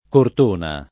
Cortona [ kort 1 na ]